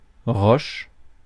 Roche-en-Forez (French pronunciation: [ʁɔʃ]
Fr-roche.ogg.mp3